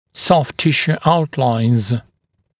[sɔft ‘tɪʃuː ‘autlaɪnz] [-sjuː][софт ‘тишу: ‘аутлайнз] [-сйу:]контуры мягких тканей